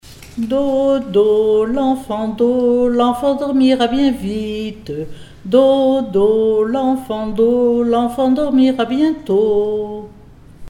enfantine : berceuse
Pièce musicale inédite